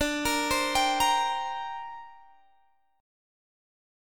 Listen to D7sus4#5 strummed